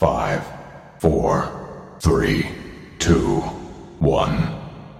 PLAY countdown 5 seconds
5-second-countdown.mp3